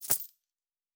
Coin and Purse 10.wav